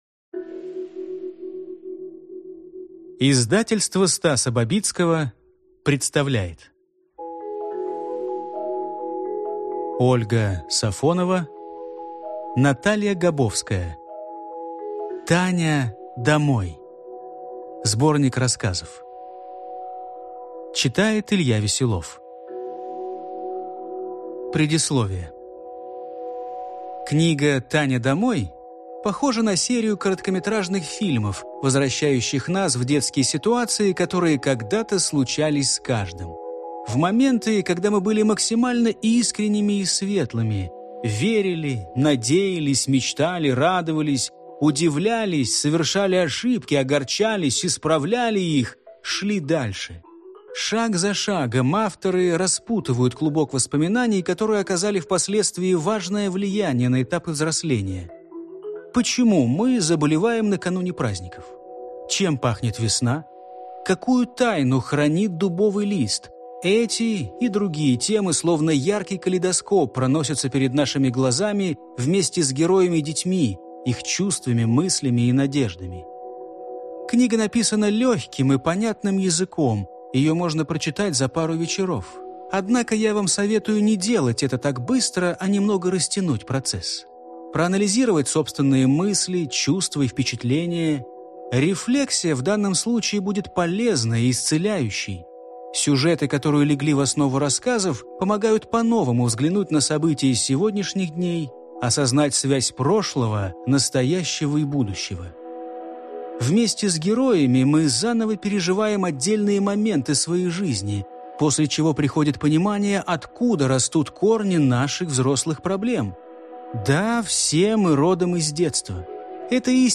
Аудиокнига Таня, домой!